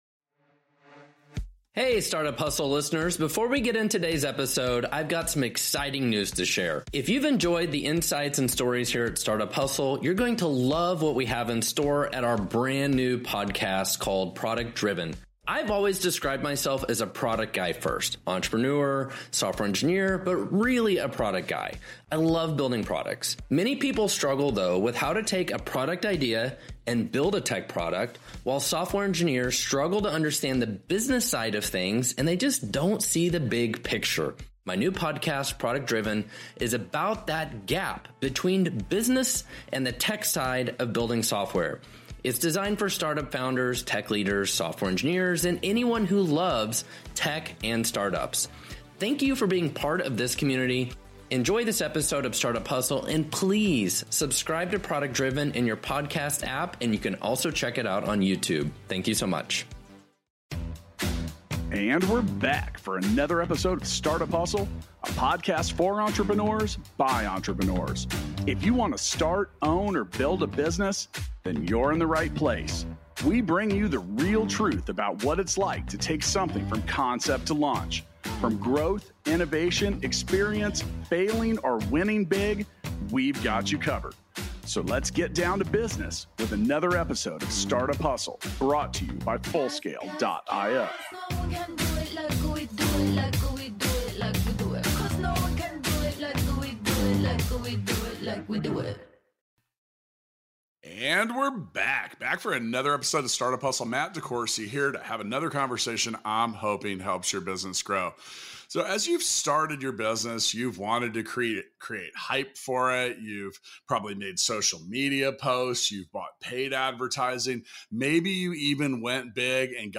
a public relations firm for a conversation centered around earned PR. Listen to them discuss the components that make good earned PR. Hear why relationships are such a huge part of PR and why it’s so hard to manage your own public relations.